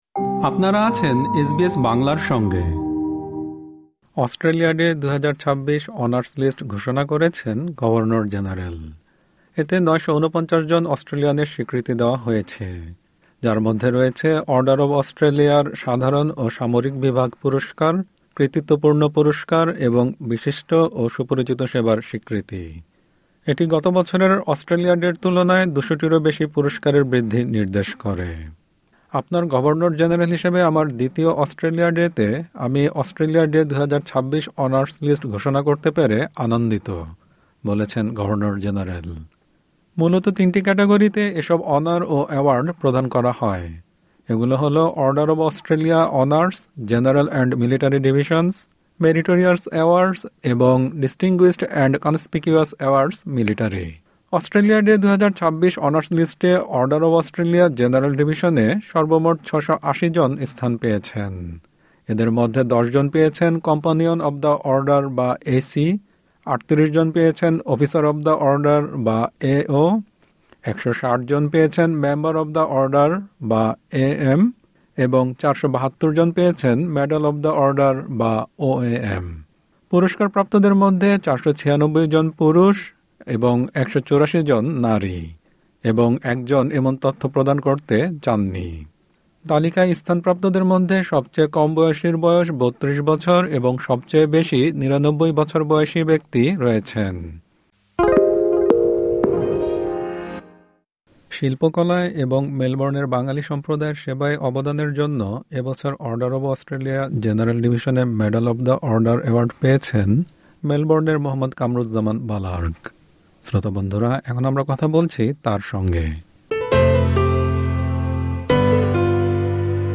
এসবিএস বাংলার সঙ্গে কথা বলেছেন তিনি।